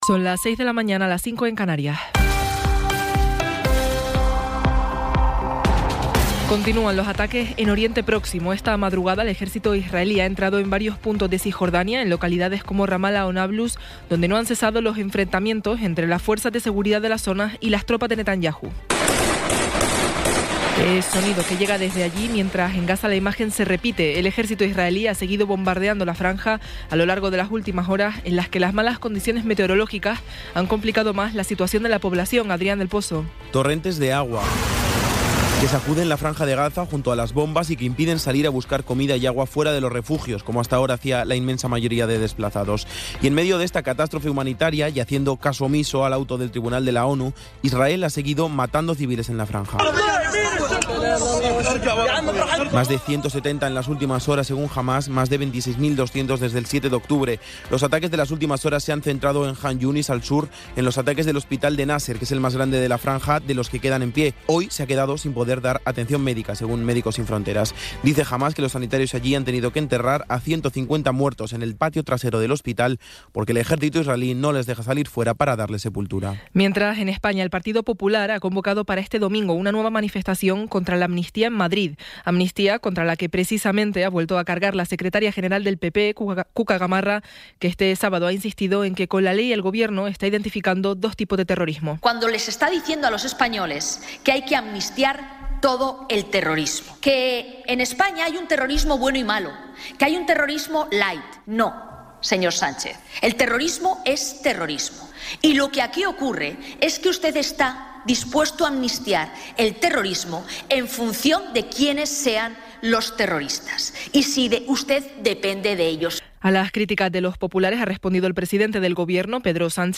Resumen informativo con las noticias más destacadas del 28 de enero de 2024 a las seis de la mañana.